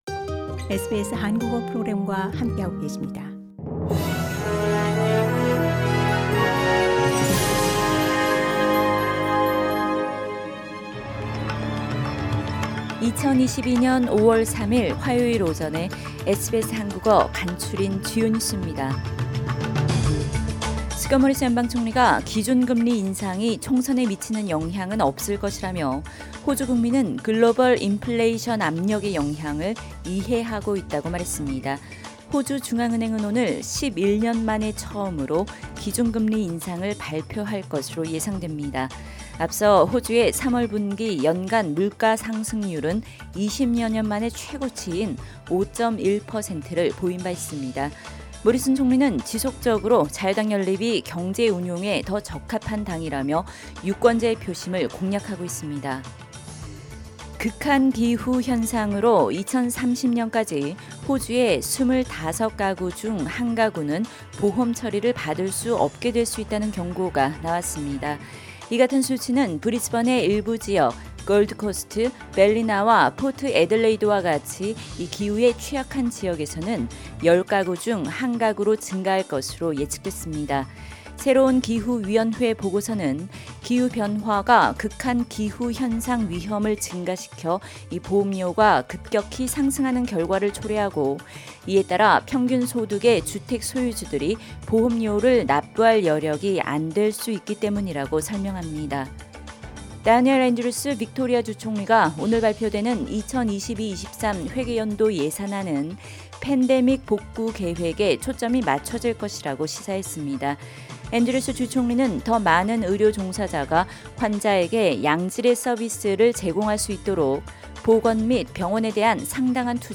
SBS 한국어 아침 뉴스: 2022년 5월 3일 화요일